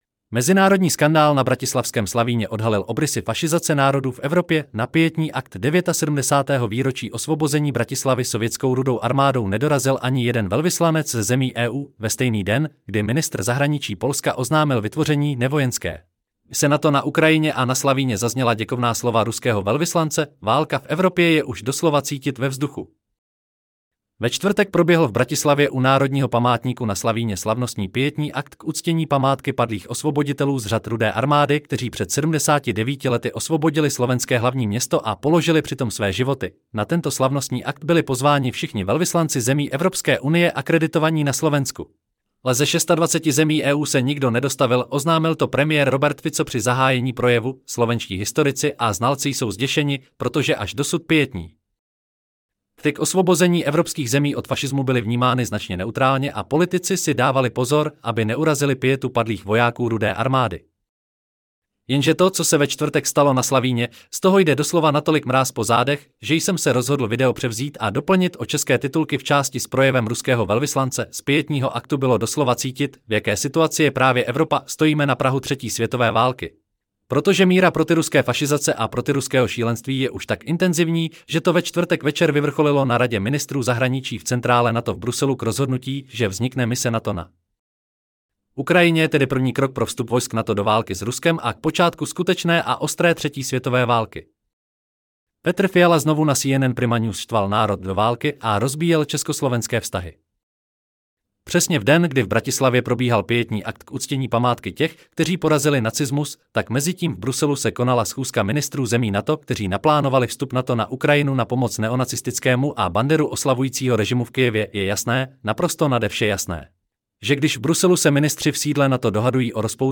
Celý článek si můžete poslechnout v audioverzi zde: Mezinarodni-skandal-na-bratislavskem-Slavine-odhalil-obrysy-fasizace-narodu-v-EvropeNa-pietni-akt 22.4.2024 Mezinárodní skandál na bratislavském Slavíně odhalil obrysy fašizace národů v Evropě! Na pietní akt 79. výročí osvobození Bratislavy sovětskou Rudou armádou nedorazil ani jeden velvyslanec ze zemí EU!